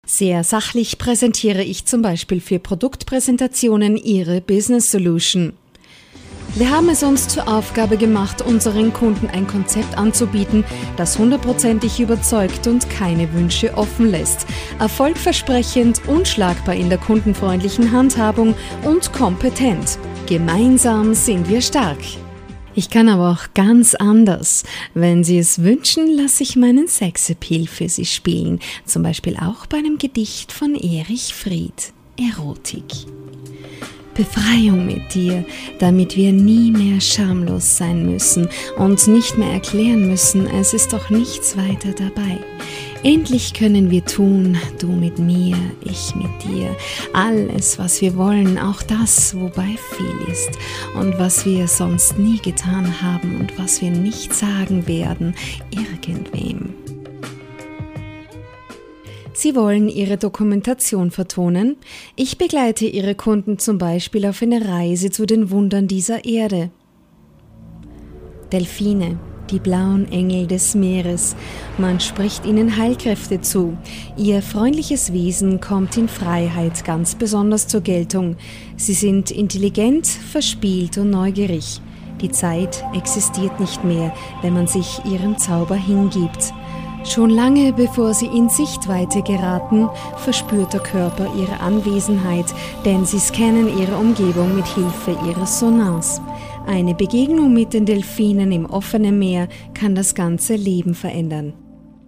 Stimmbeschreibung: Warm, einfühlsam, erotisch/sexy, verführerisch, werblich, jung oder reif, elegant, sympatisch, souverän, geheimnissvoll, sachlich/kompetent, erzählerisch, selbstbewusst und wandlungsfähig.
Sprecherin und Sängerin. Stimme: Warm, einfühlsam, erotisch / sexy, verführerisch, jung oder reif, elegant, sympatisch, souverän, geheimnissvoll,
Sprechprobe: Industrie (Muttersprache):